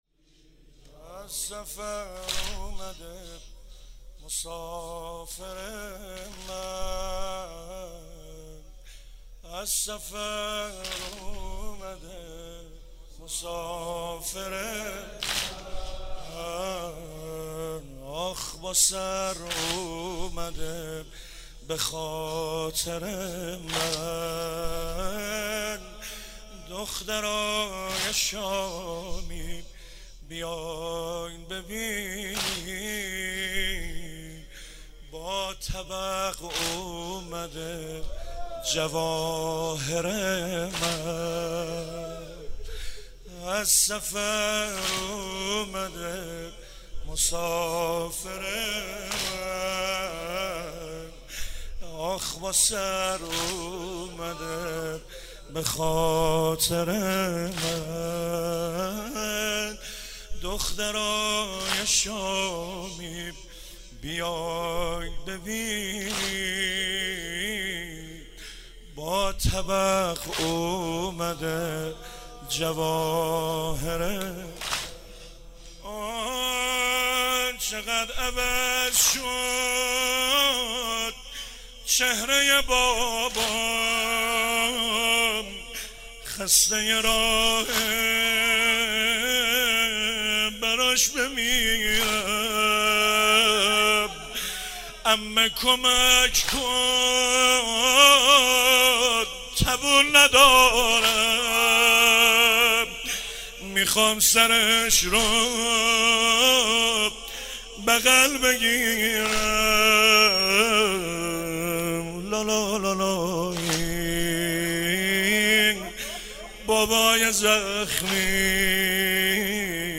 شهادت حضرت رقیه سلام الله علیها - واحد - از سفر اومده مسافر من